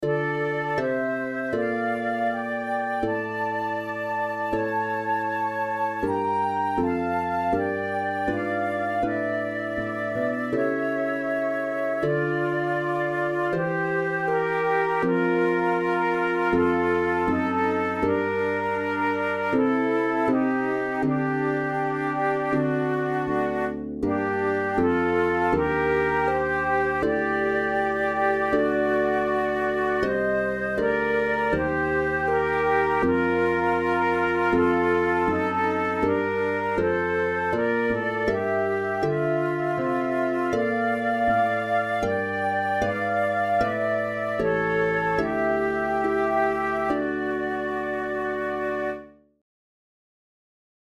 InstrumentationTwo Flutes and Piano
KeyD minor
Time signature4/4
Tempo80 BPM
Contemporary